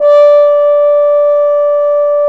Index of /90_sSampleCDs/Roland L-CDX-03 Disk 2/BRS_French Horn/BRS_Mute-Stopped
BRS F.HORN0Q.wav